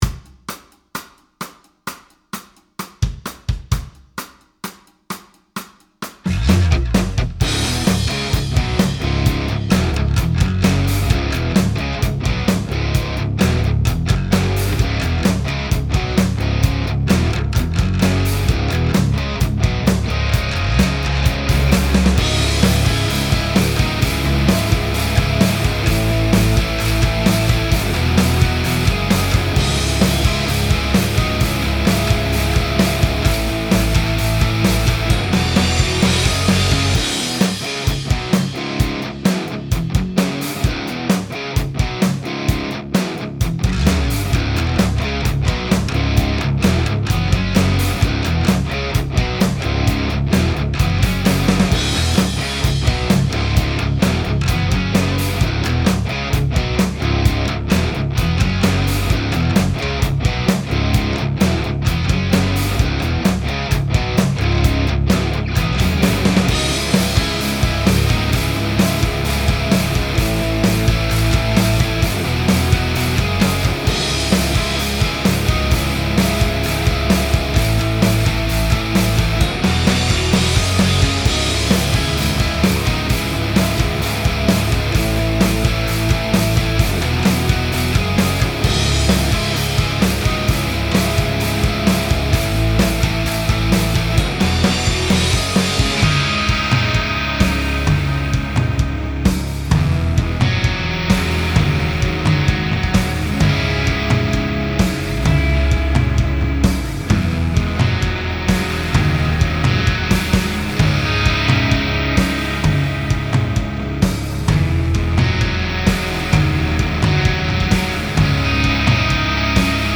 Included Original Backing Track For You To Jam Along:
No post processing was added.